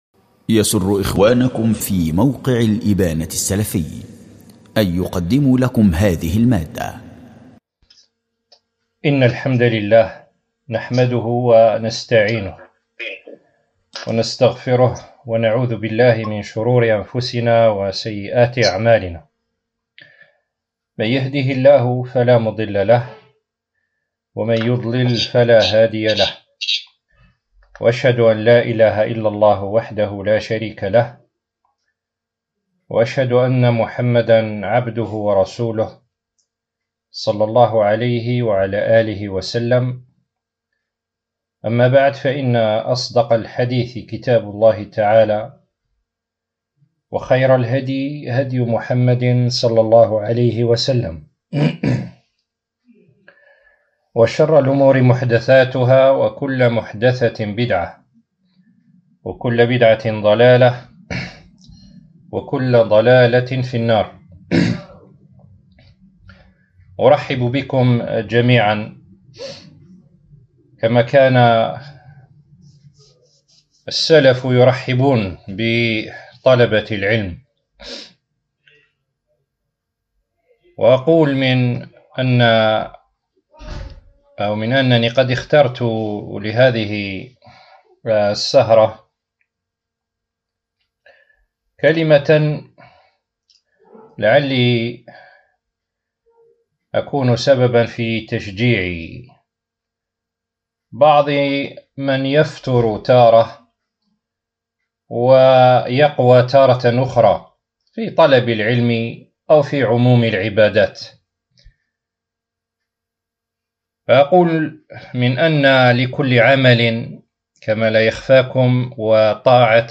🔹للإخوة بمدينة قسنطينة 🗓ليلة الإثنين 2 ذو القعدة 1447 هــ الموافق لـ 20 أفريل 2026 م
الشَّيخ: الملفات الصَّوتية: الملف الصَّوتي تحميل لكل عامل شرة ولكل شرة فترة ▼ التفريغات: اسم الملف تحميل 🔹للإخوة بمدينة قسنطينة 🗓ليلة الإثنين 2 ذو القعدة 1447 هــ الموافق لـ 20 أفريل 2026 م الوسوم: دروس ومحاضرات